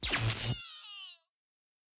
electrocuted_vilgelm.ogg